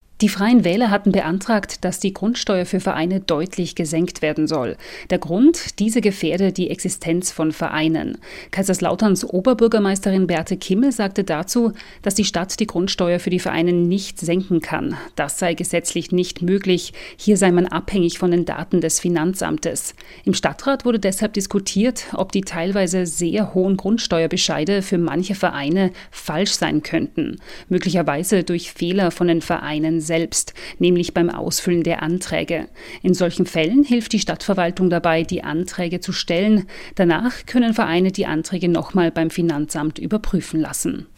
Über dieses Thema berichtete das SWR Studio Kaiserslautern in den SWR4-Regionalnachrichten am 27.5.2025 um 6:30 Uhr.